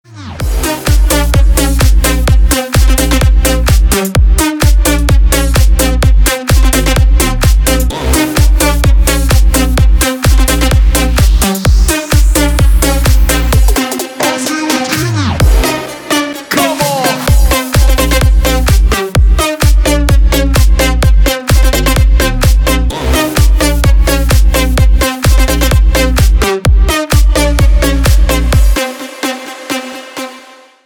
Клубные темы для рингтона
• Песня: Рингтон, нарезка